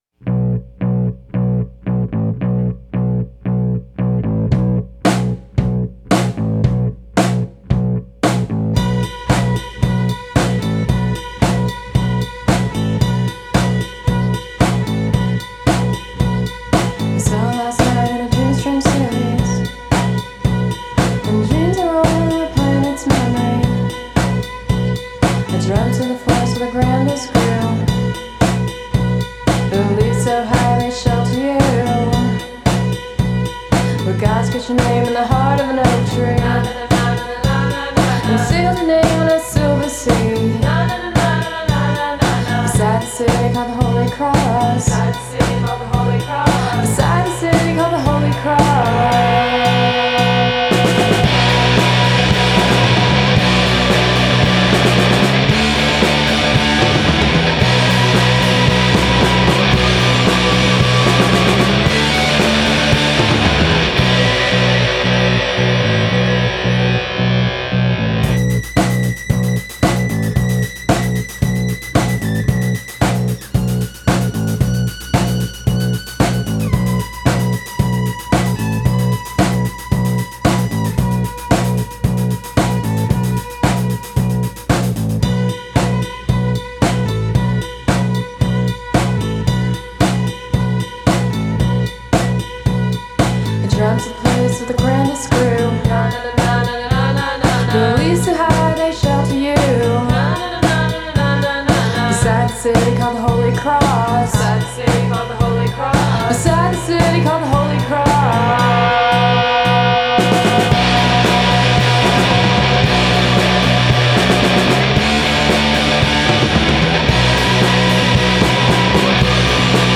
the vast pool between deep space and deep bass